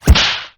slapstickBigPunch.ogg